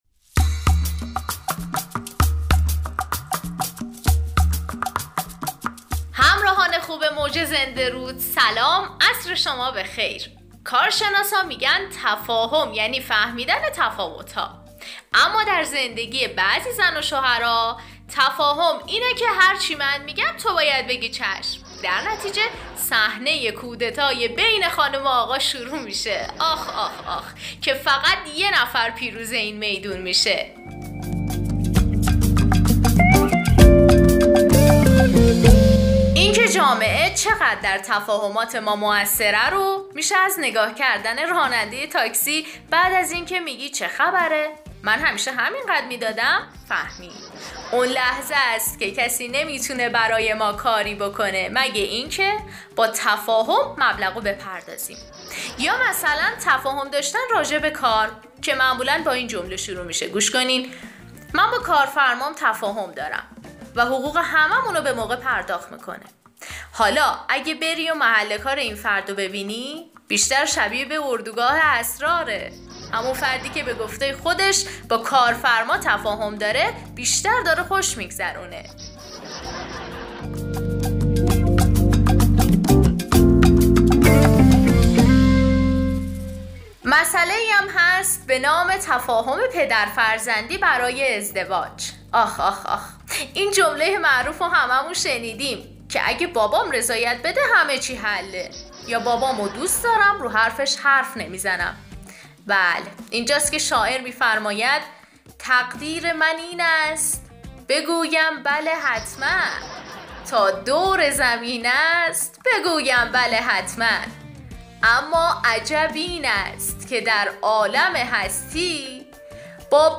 پخش از رادیو جهان بین ( شهرکرد)